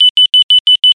Marker Beacons